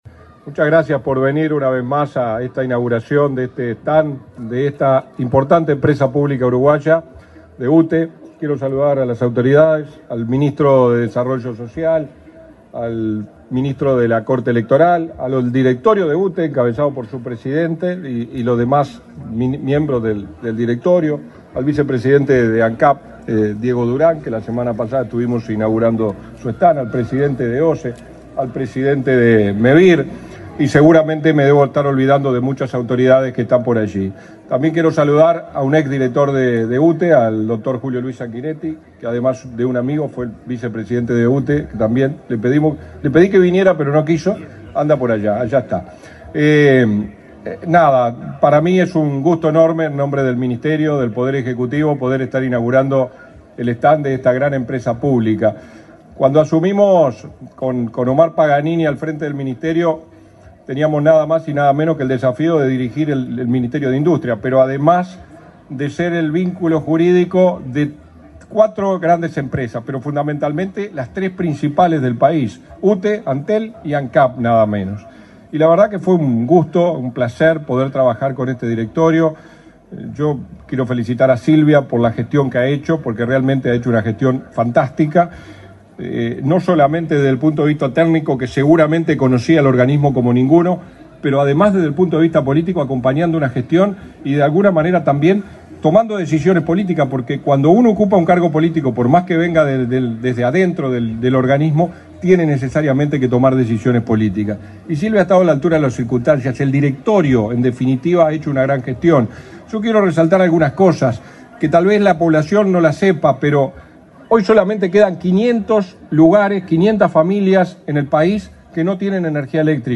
Palabras de autoridades en la inauguración de stand de UTE en la Expo Prado 2024
El subsecretario de Industria, Walter Verri, y el ministro de Ganadería, Fernando Mattos, participaron en la inauguración del stand de UTE en la Expo